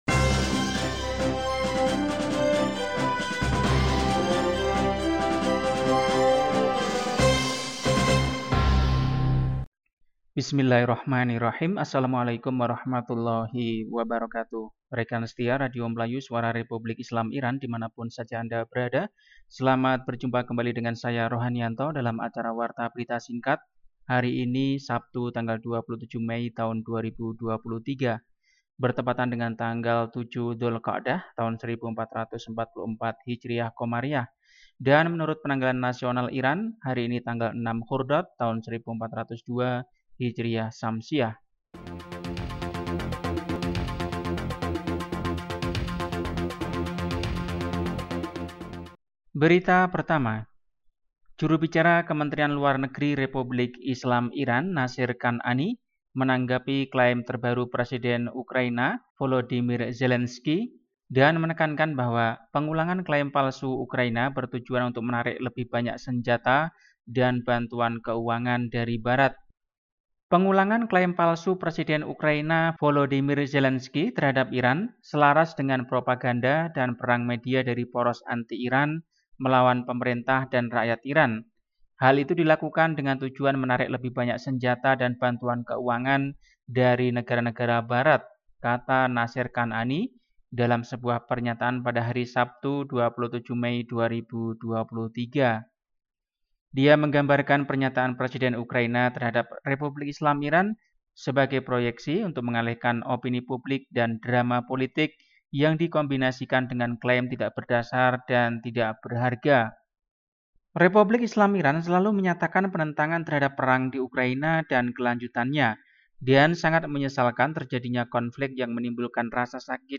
Warta Berita 27 Mei 2023
Warta berita hari ini, Sabtu, 27 Mei 2023